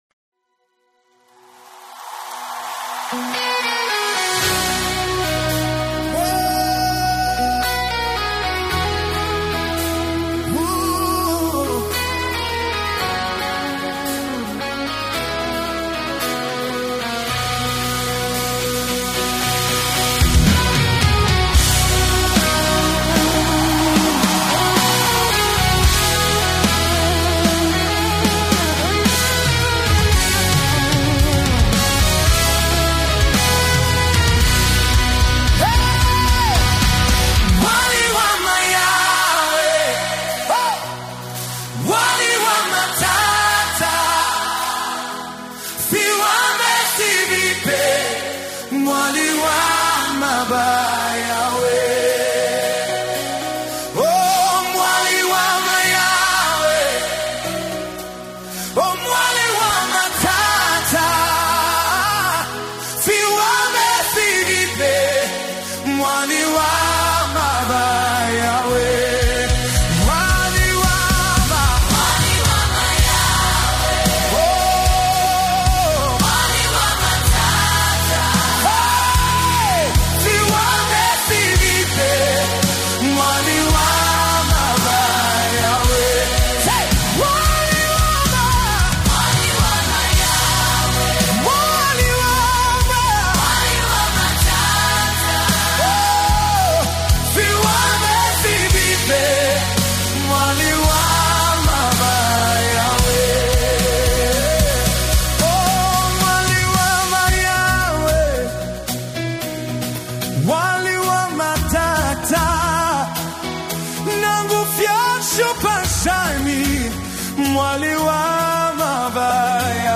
🎼 GENRE: ZAMBIAN GOSPEL MUSIC